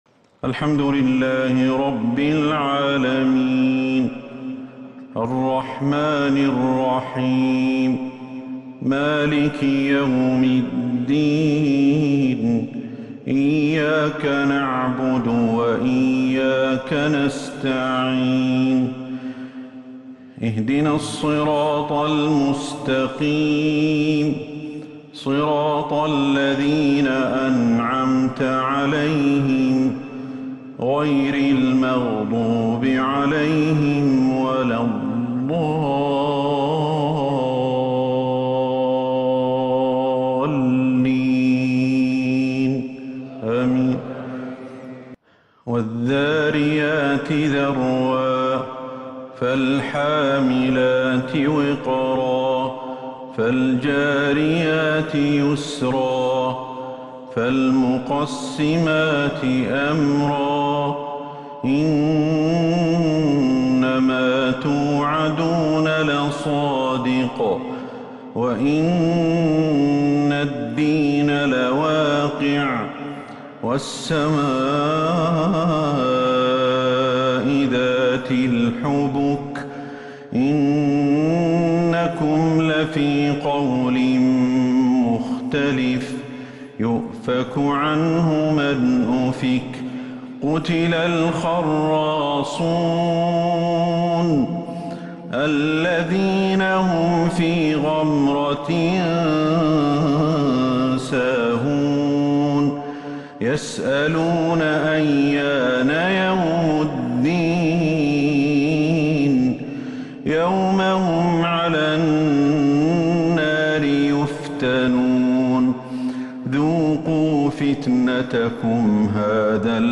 فجر الأحد 3-8-1443هـ سورة الذاريات كاملة | Fajr prayer from Surat Adh-Dhaariyat 6-3-2022 > 1443 🕌 > الفروض - تلاوات الحرمين